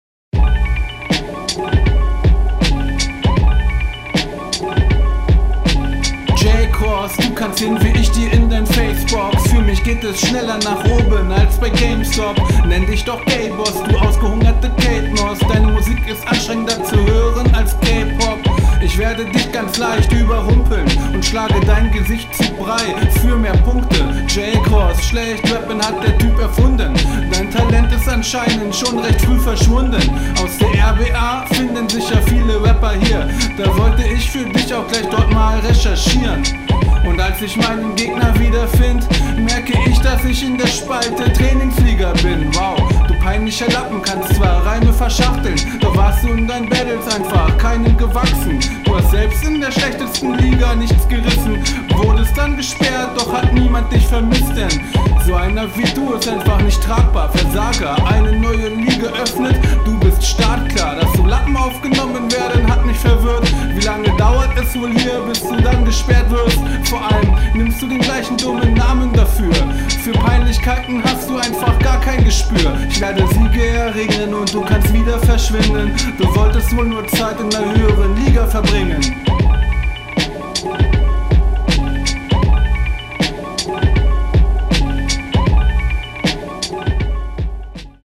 Auf der Stimme ist sehr viel Hall, fällt mir direkt auf.
Also deine Soundqualität ist akzeptabel aber definitiv ausbaufähig.